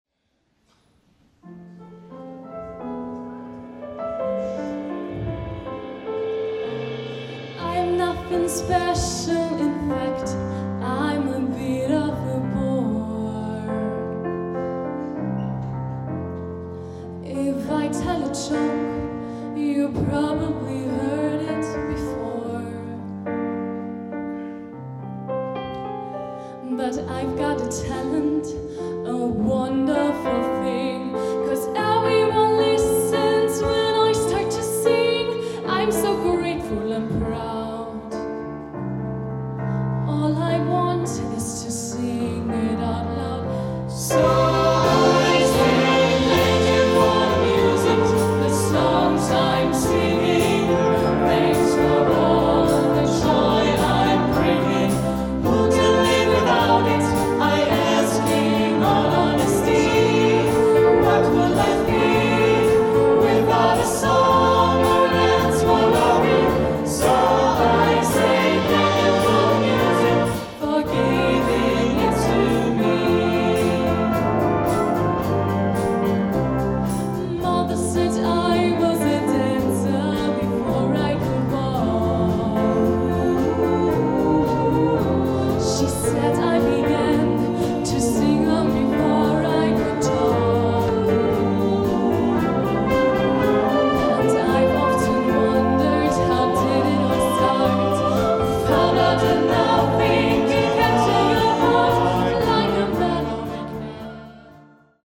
Gattung: Evergreen
Besetzung: Blasorchester